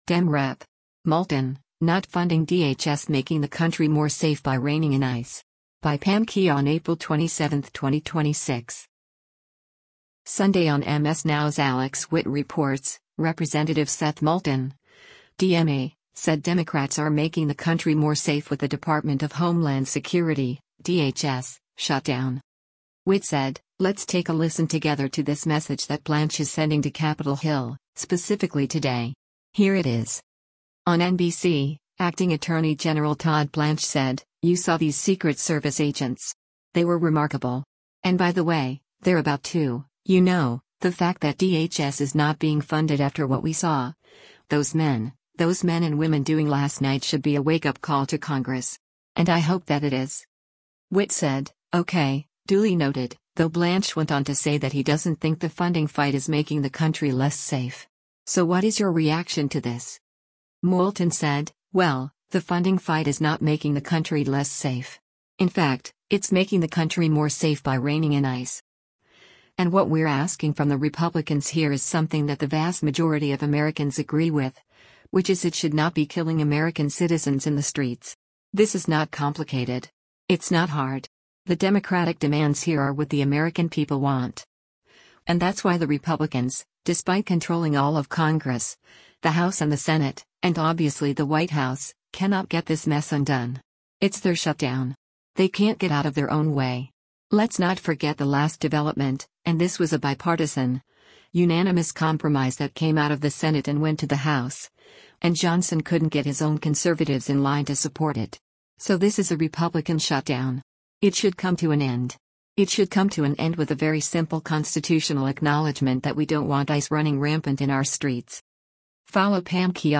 Sunday on MS NOW’s “Alex Witt Reports,” Rep. Seth Moulton (D-MA) said Democrats are making the country “more safe” with the Department of Homeland Security (DHS) shutdown.